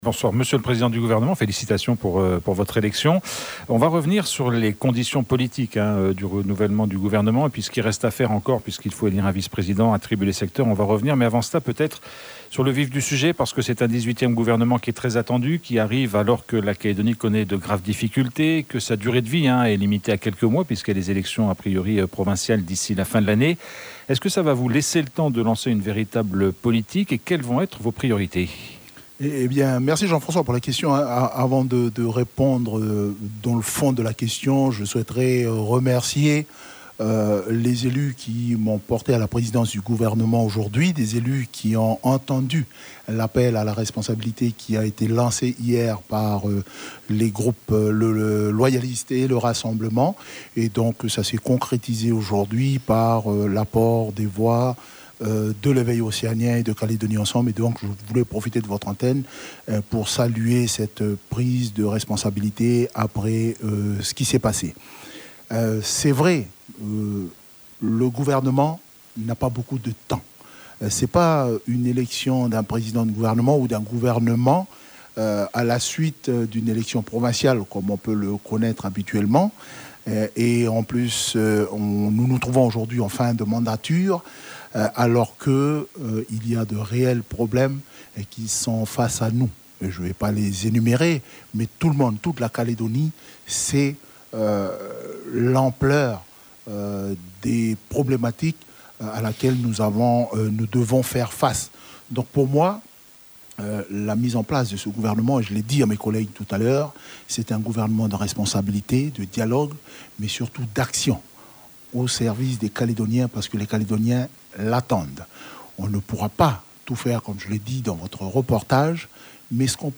Le nouveau président du gouvernement, Alcide Ponga, invité sur RRB